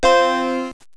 snd_8410_Mac II startup sound.wav